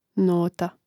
nóta nota